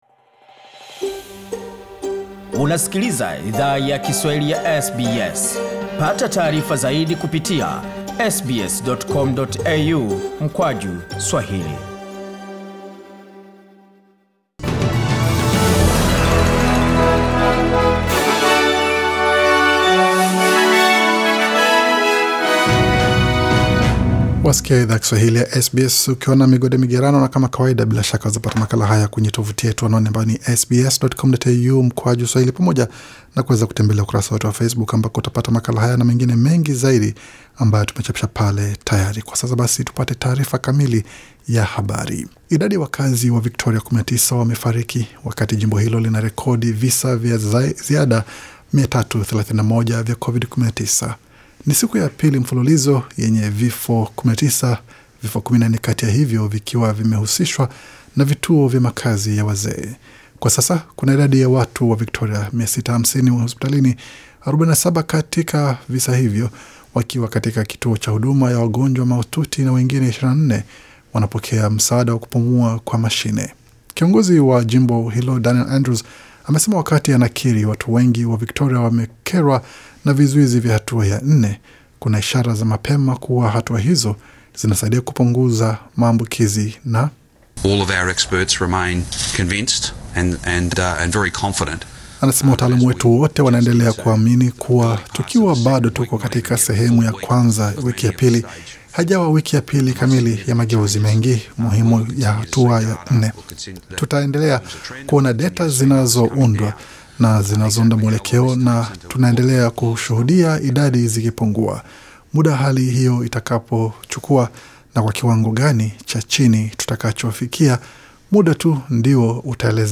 Taarifa ya habari 11 Agosti 2020